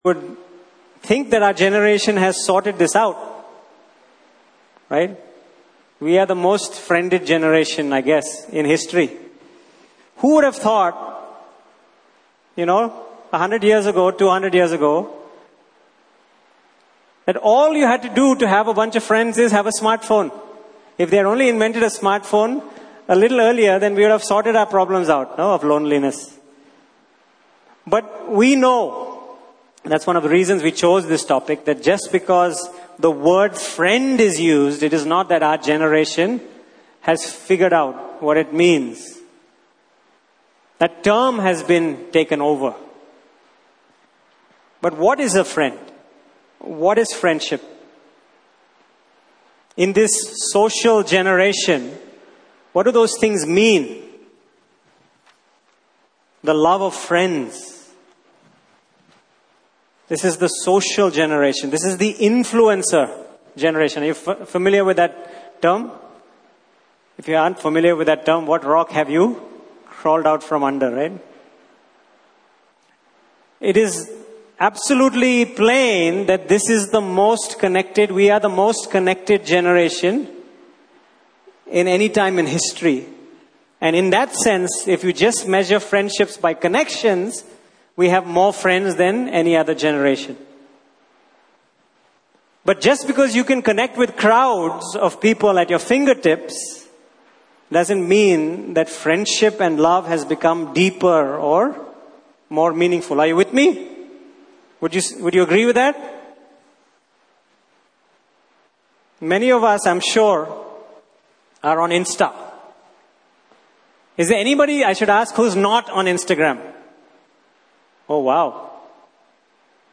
Service Type: Main Session